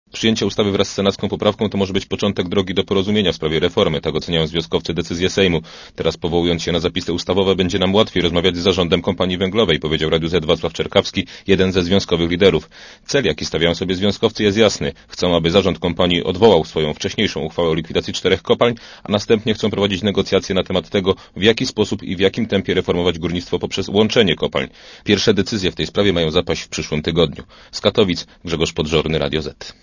Posłuchaj korespondenta Radia Zet